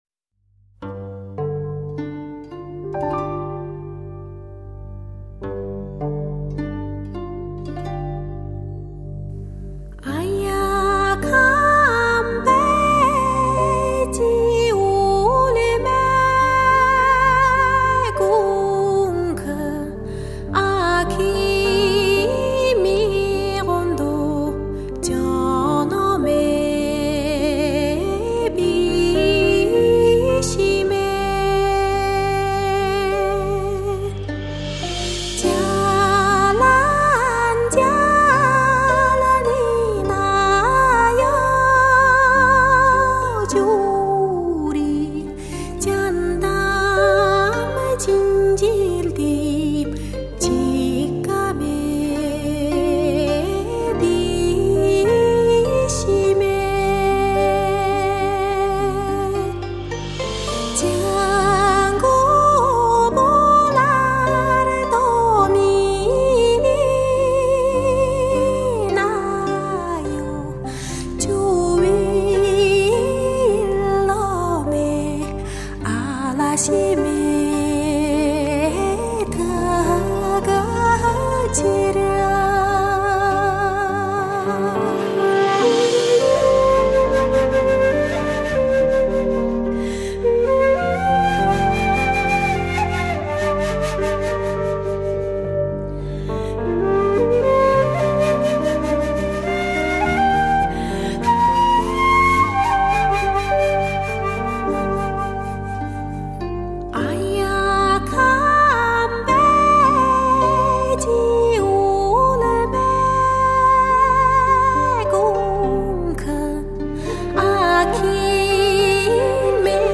史上最动听最发烧的蒙古民谣！